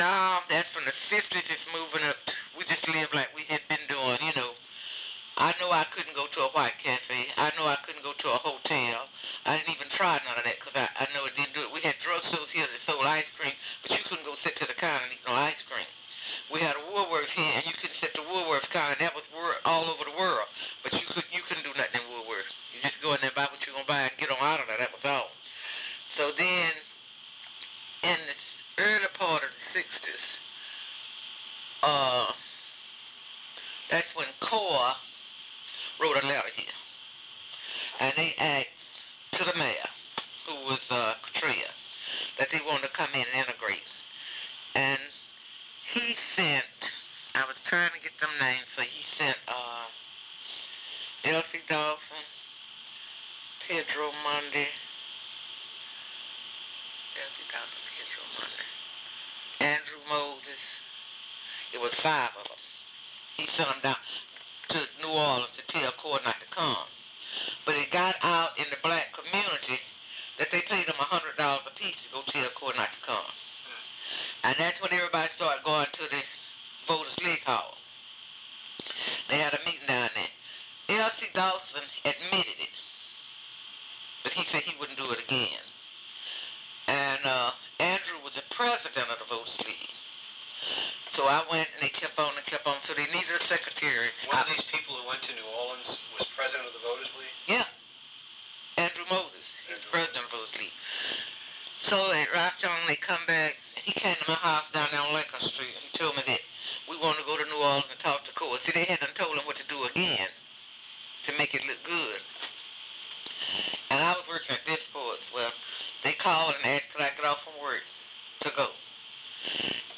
Audio Interviews: Excerpts